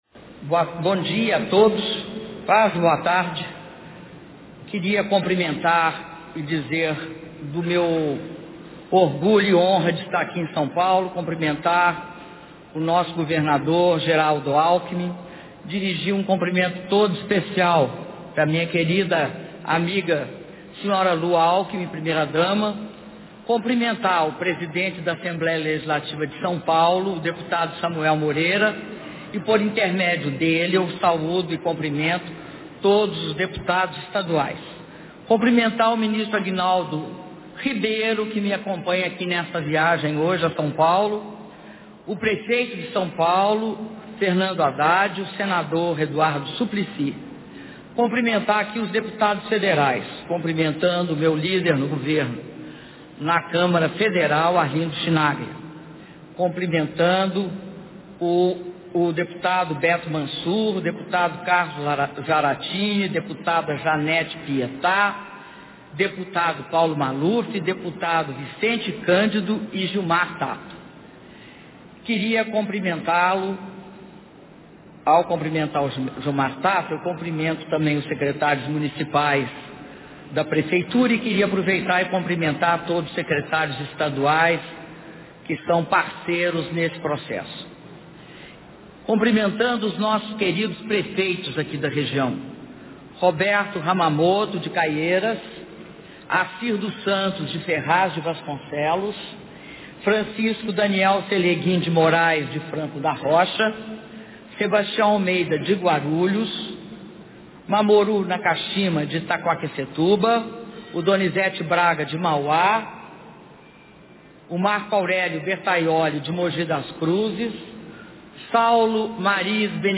Áudio do discurso da Presidenta na cerimônia de anúncio de investimentos em mobilidade urbana - São Paulo/SP (30min49s)
Discurso da Presidenta Dilma Rousseff na cerimônia de anúncio de investimentos do PAC Mobilidade Urbana - São Paulo/SP